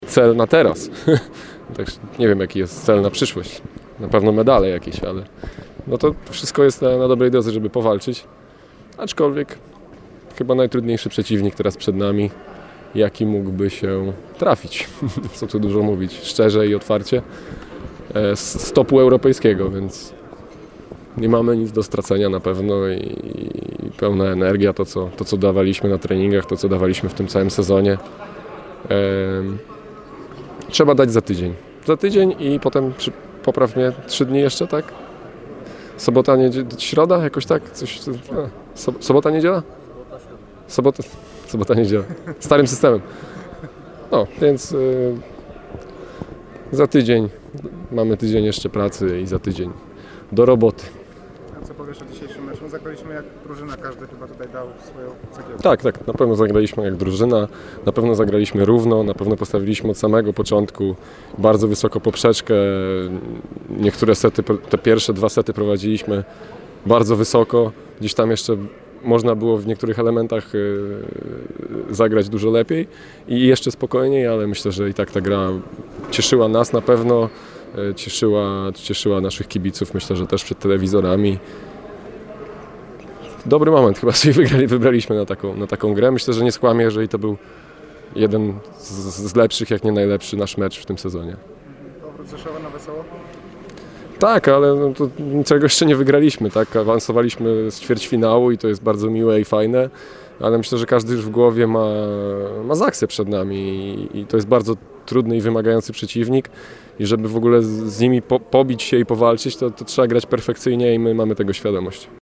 Wypowiedzi po meczu z Asseco Resovią (AUDIO + WIDEO)
PGE Skra Bełchatów ponownie pokonała Asseco Resovię i zameldowała się w półfinale PlusLigi. Wypowiedzi Karola Kłosa i Dusana Petkovicia, który został MVP spotkania.